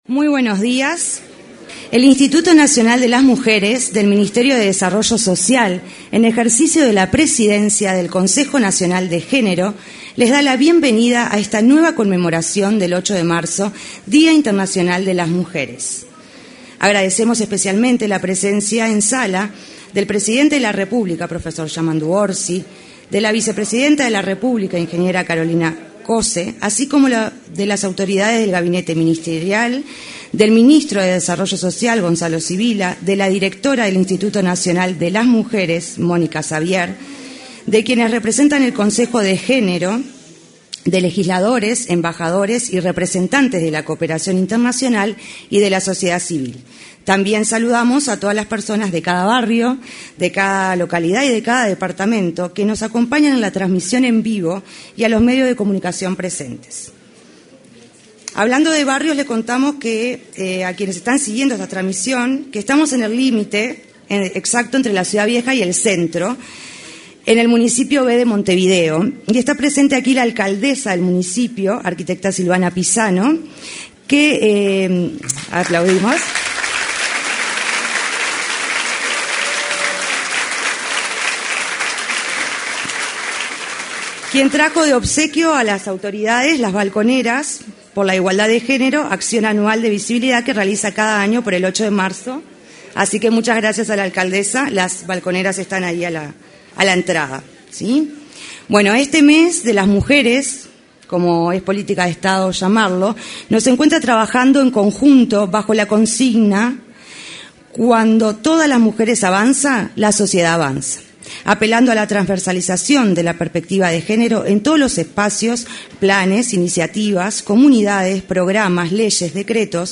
Conmemoración del Día Internacional de las Mujeres 10/03/2025 Compartir Facebook X Copiar enlace WhatsApp LinkedIn Con la presencia del presidente de la República, profesor Yamandú Orsi, este lunes 10 de marzo en el salón de actos de la Torre Ejecutiva, se realizó la conmemoración del Día Internacional de las Mujeres. En la oportunidad, además del presidente, se expresaron el ministro de Desarrollo Social, Gonzalo Civila; la vicepresidenta de la República, Carolina Cosse, y la directora del Instituto Nacional de las Mujeres, Mónica Xavier.